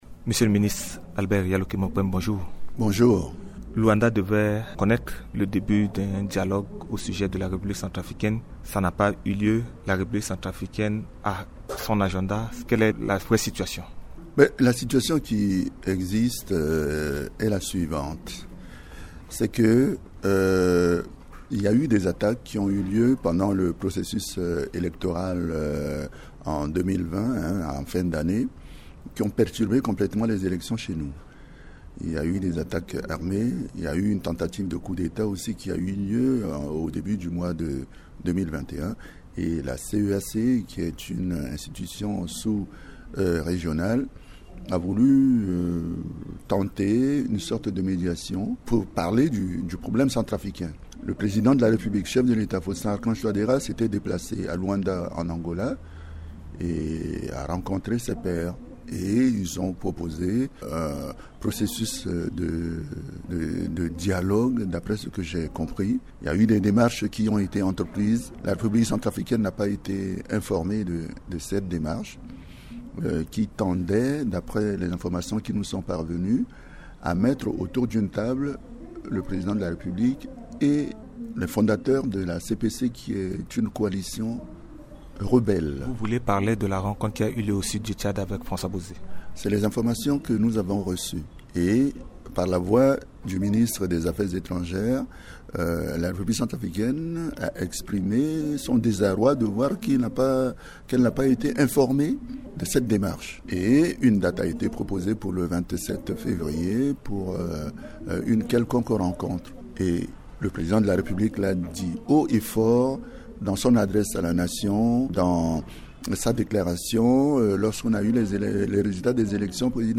Dans son interview à Radio Ndeke Luka, le ministre porte-parole de la Présidence Albert Yaloké Mokpeme a indiqué que le président Faustin Archange Touadera va dialoguer avec l’opposition démocratique responsable après son investiture. Il écarte l’idée de dialoguer avec la CPC. Son entretien intervient après l’échec de la rencontre de Luanda qui devra connaitre les échanges sur la situation de la République Centrafricaine.